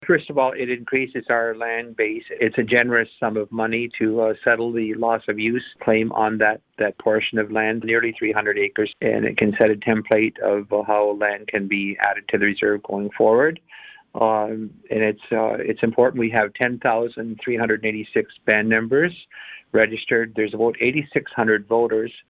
Chief Maracle tells Quinte News he can’t get into any specifics on the proposed agreement, but says it’s important for all eligible band members to have a voice on the issue.